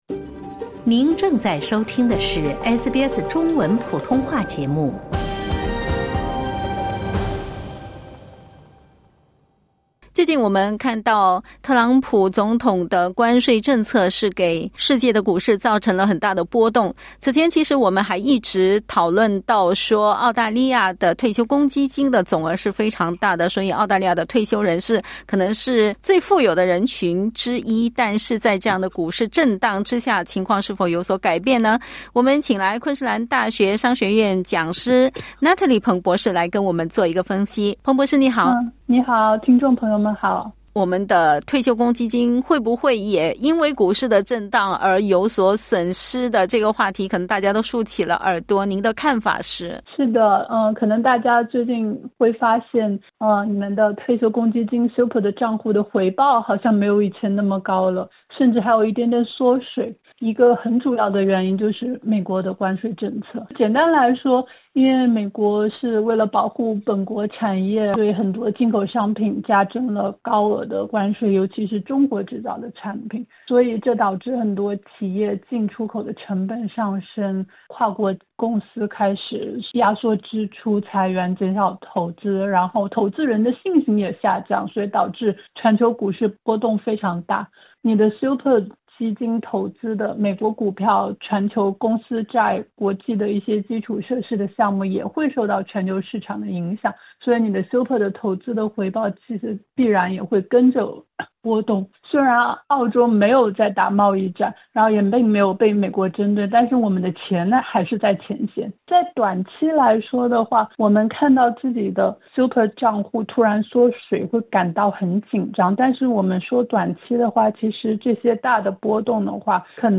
（点击音频收听详细采访）